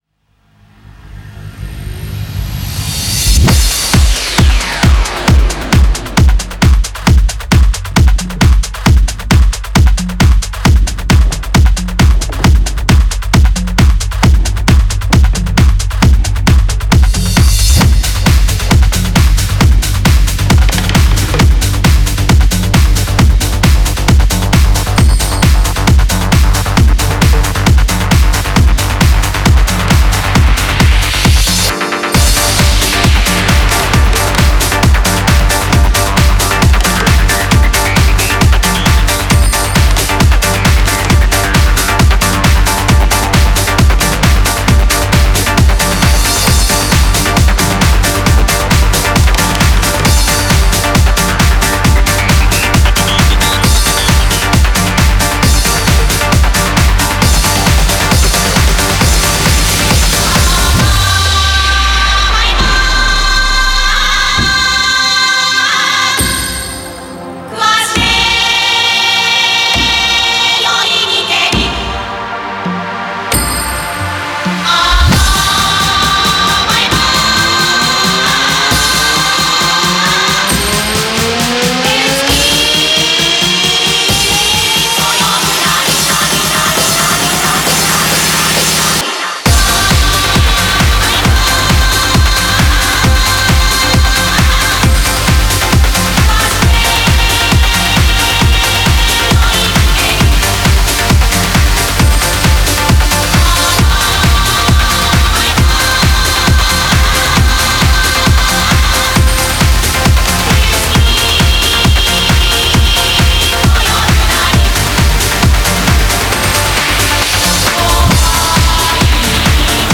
Genre : Trance
BPM : 134 BPM
Release Type : Bootleg Remix / Edit